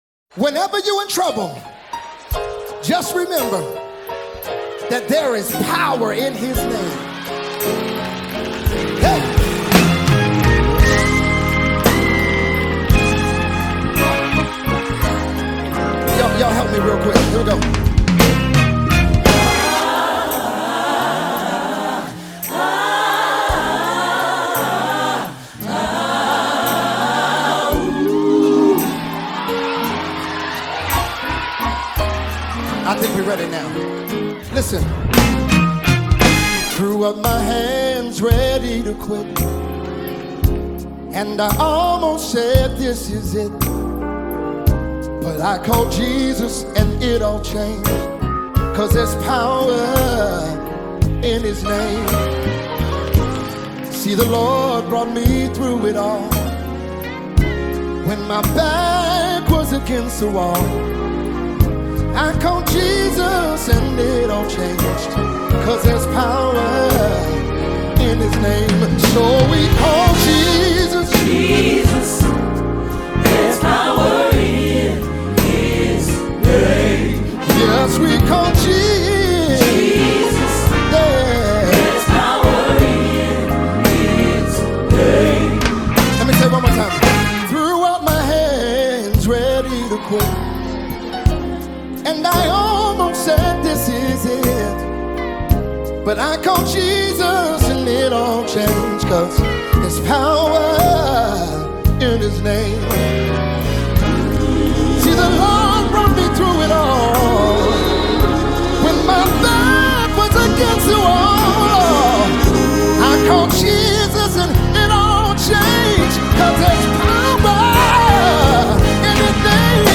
Mp3 Gospel Songs